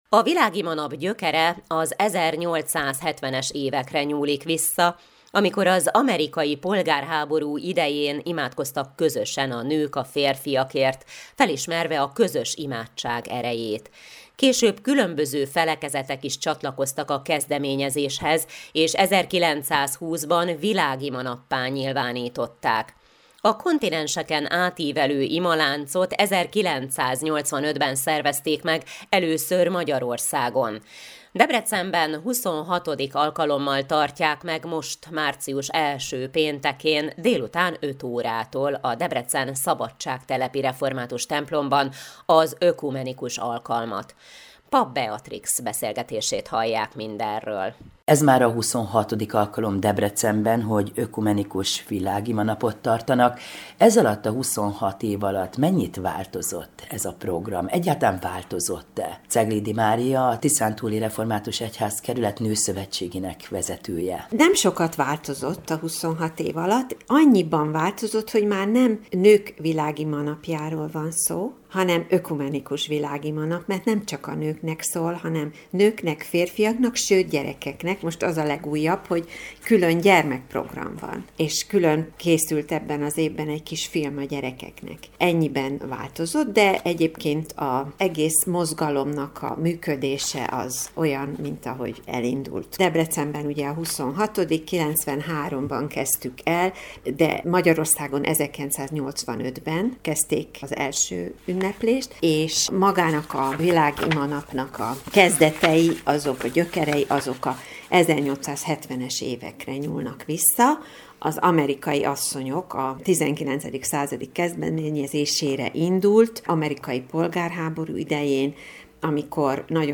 Világimanap a szabadságtelepi református templomban
7-20-okumenikus-vilagimanap.mp3